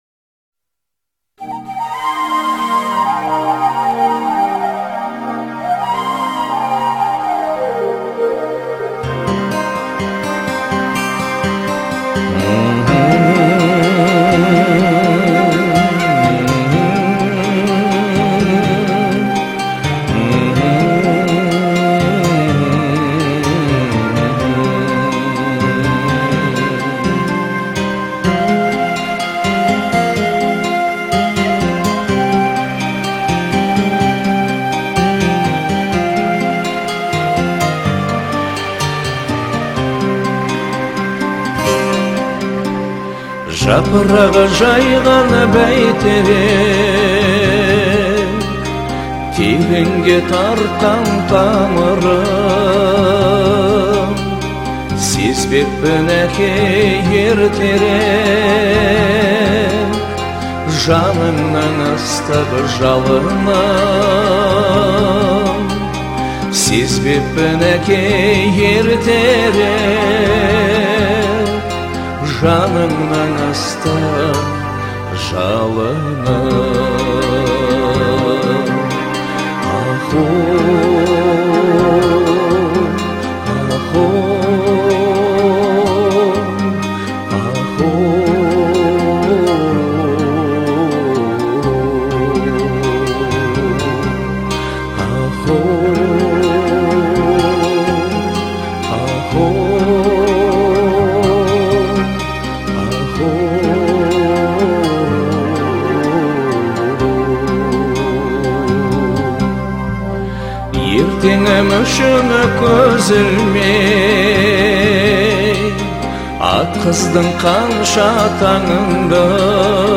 трогательная песня казахского исполнителя
которая принадлежит к жанру поп-музыки.
Звучание композиции сочетает в себе мелодичность и нежность